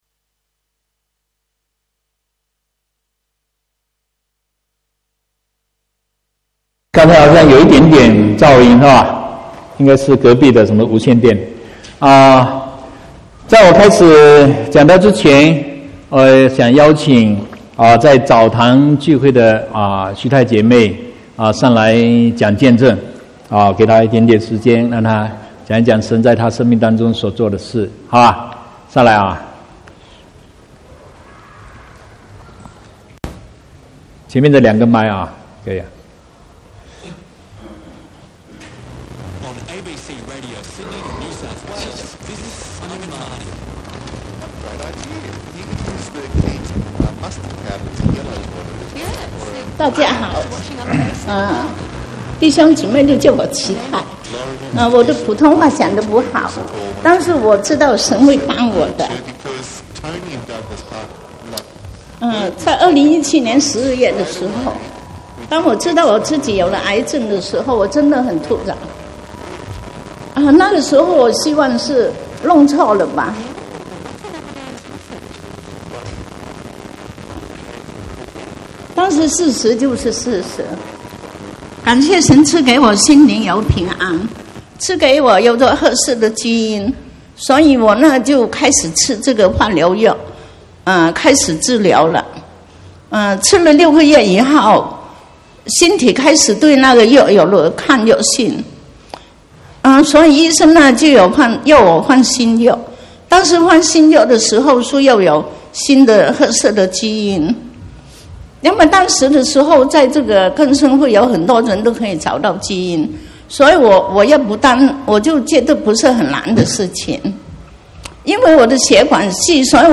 2/6/2019 國語堂講道